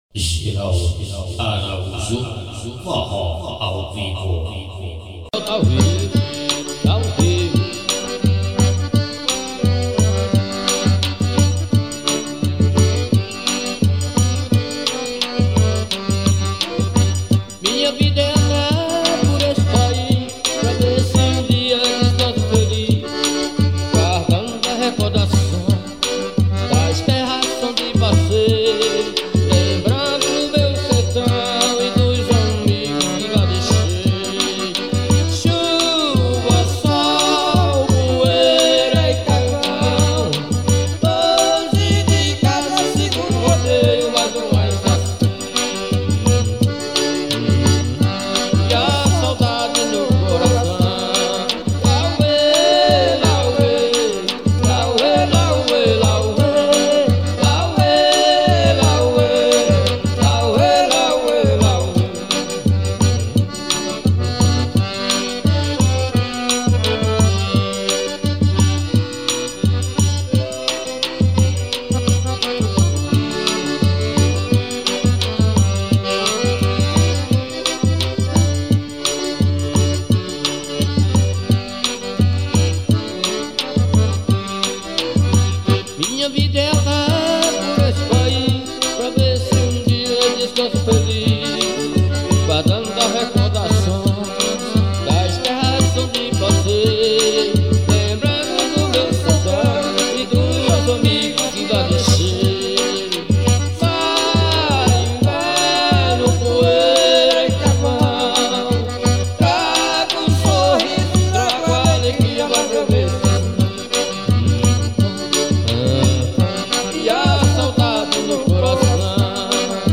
GRAVAÇÃO CASEIRA